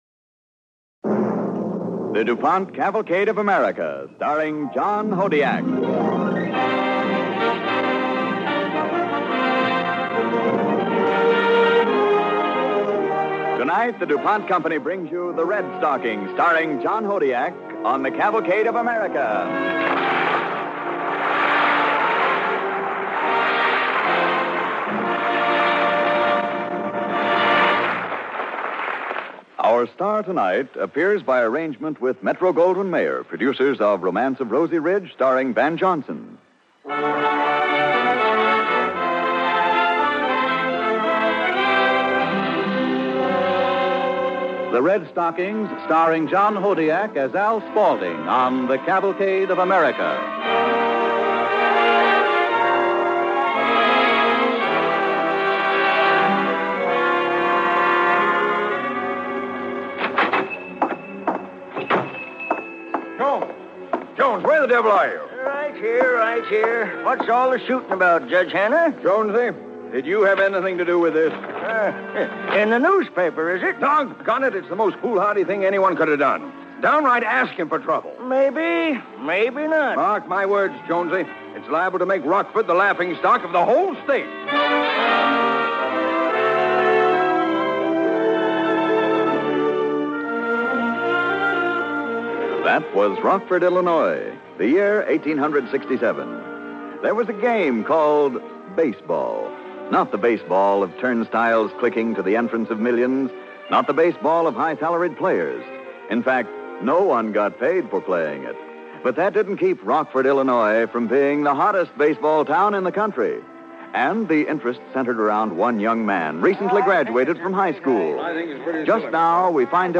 Cavalcade of America Radio Program
The Red Stockings, starring John Hodiak and Jane Morgan